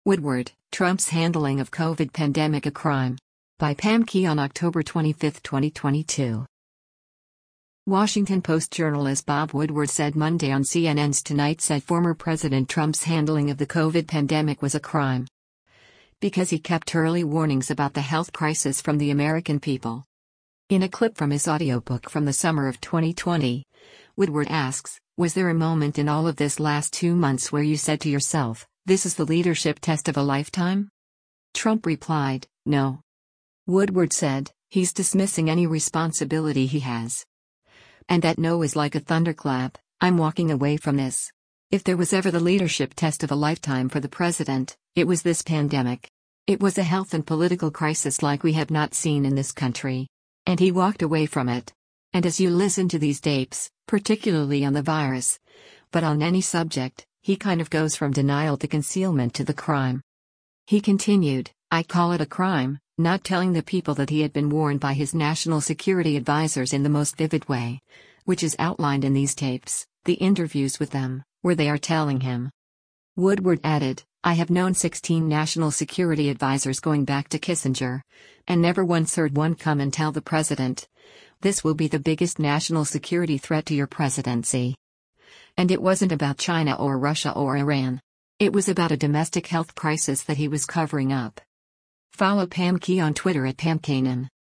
Washington Post journalist Bob Woodward said Monday on “CNN’s Tonight” said former President Trump’s handling of the COVID pandemic was a “crime.” because he kept early warnings about the health crisis from the American people.
In a clip from his audiobook from the summer of 2020, Woodward asks, “Was there a moment in all of this last two months where you said to yourself, this is the leadership test of a lifetime?”